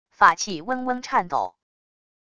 法器嗡嗡颤抖wav音频